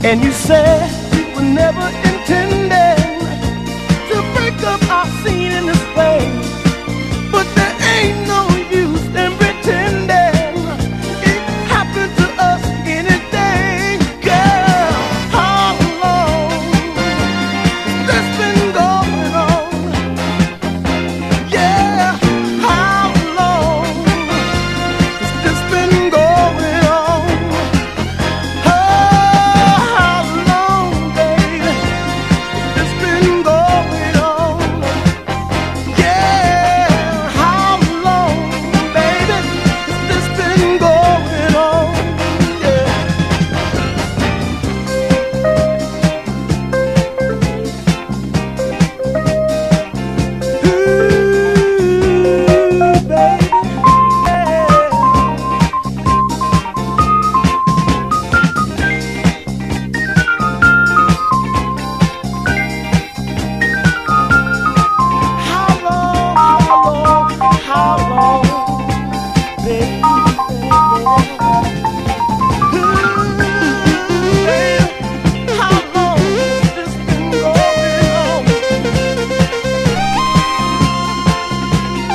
SOURTHERN SOUL / DEEP SOUL
アラバマ生まれのサザン・ディープ・ソウル・シンガー！